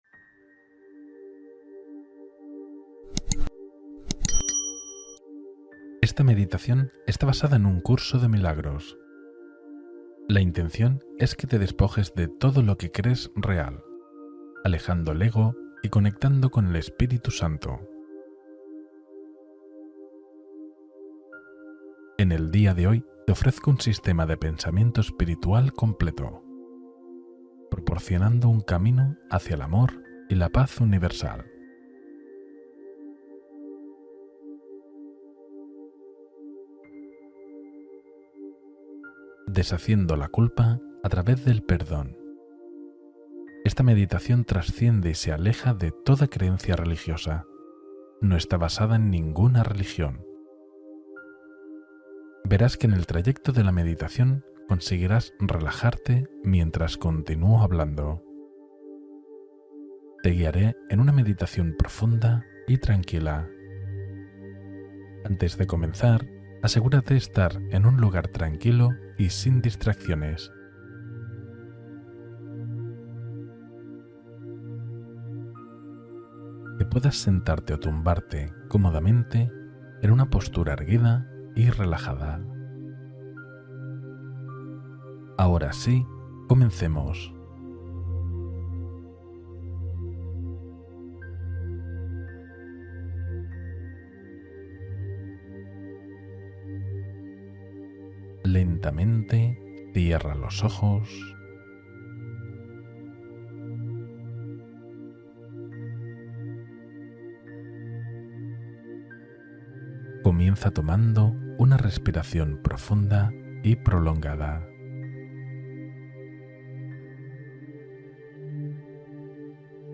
Cultiva amor y conciencia sin juicio en esta profunda meditación